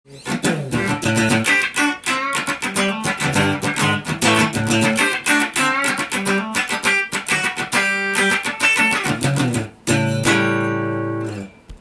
ジャキジャキした音かな？と思いきや、低音が出てくる不思議なテレキャスです。
リアピックアップで弾きましたが
ジャキジャキ感と低音の重低音が入り混じった良い感じの音がしました！